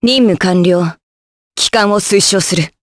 Hilda-Vox_Victory_jp.wav